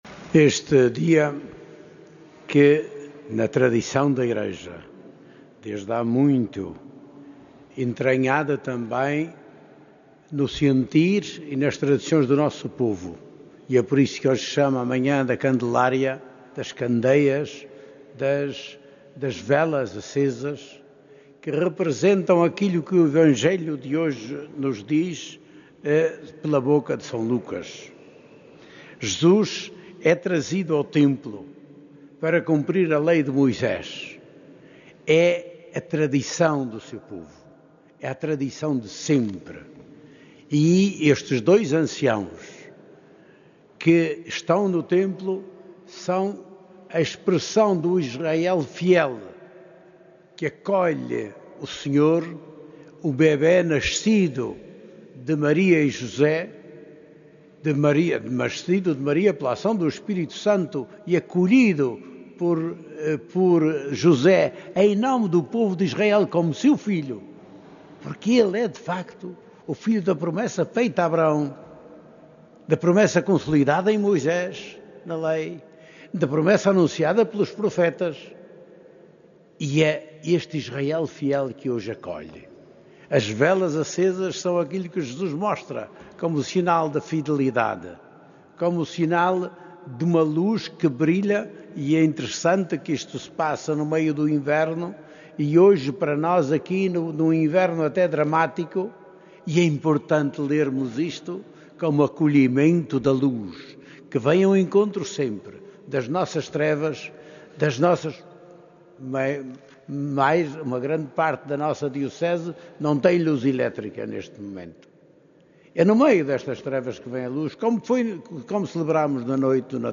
Na celebração da Apresentação do Senhor no Templo, D. José Ornelas, perante cerca de 200 religiosos de institutos próximos ao Santuário de Fátima, presentes esta manhã na Basílica de Nossa Senhora do Rosário de Fátima, lembrou a importância da oração e dos gestos concretos de ajuda.